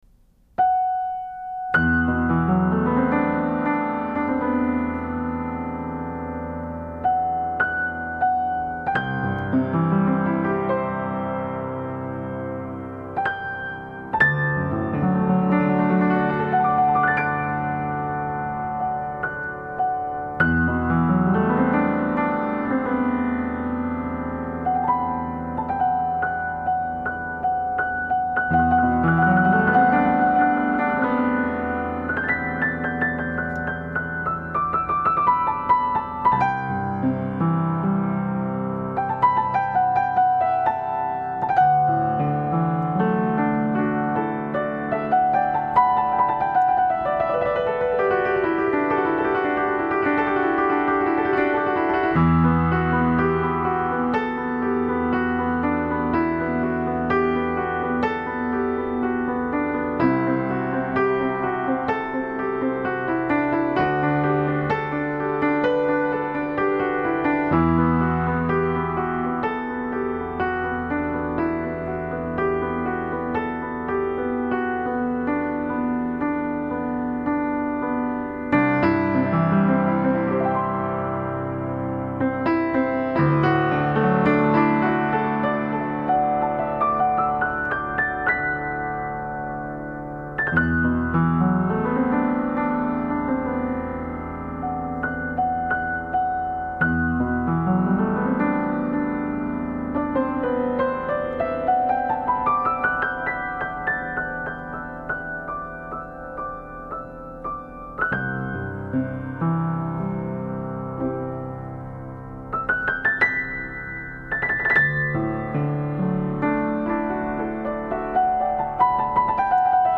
主要演奏器乐： 钢琴
乐风：新世纪、疗癒
最平静。抚慰。美丽的纯淨旋律